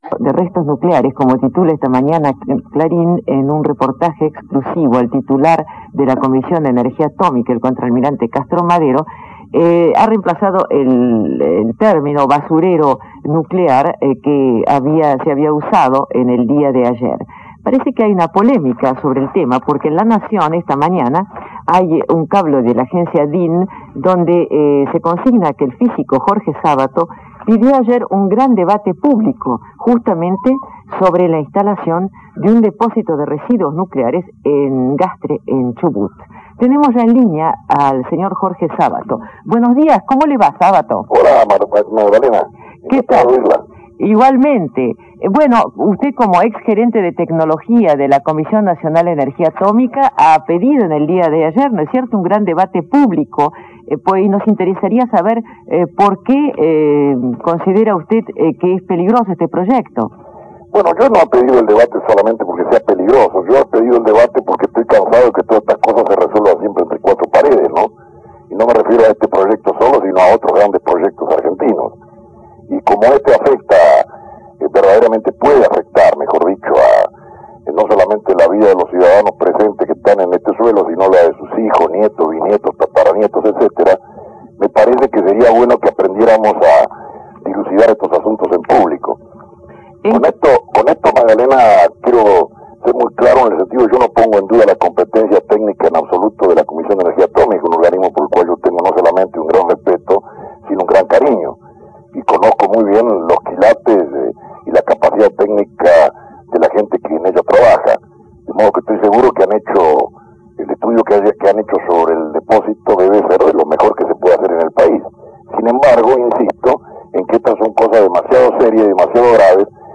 Magdalena Ruiz Guiñazú, reconocida periodista argentina, realiza una entrevista a Jorge A. Sabato en Radio Rivadavia